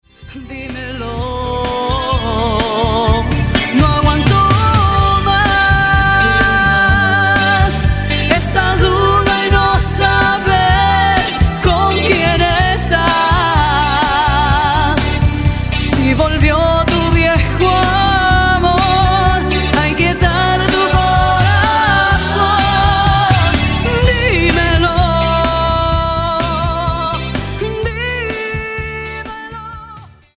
romantic and passionate songs